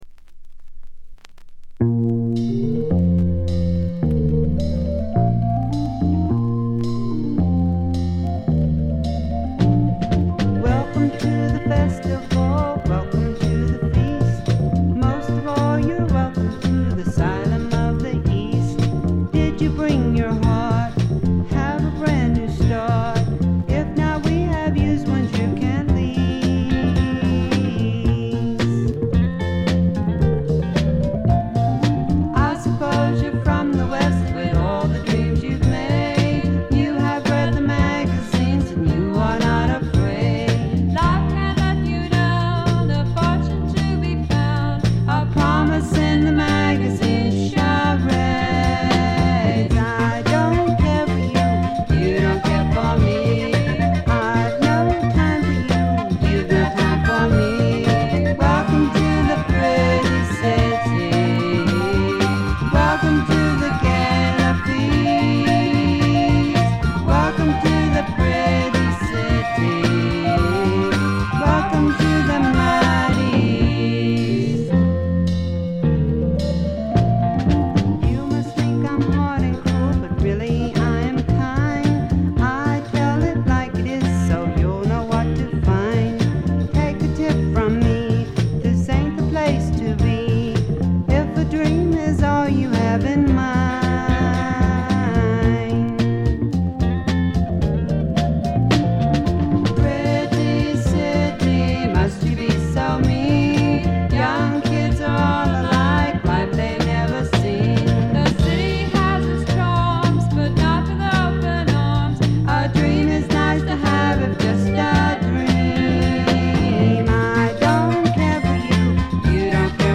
軽微なバックグラウンドノイズ、チリプチ程度。
ソフト・ロック、ソフト・サイケ、ドリーミ・サイケといったあたりの言わずと知れた名盤です。
試聴曲は現品からの取り込み音源です。